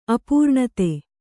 ♪ apūrṇate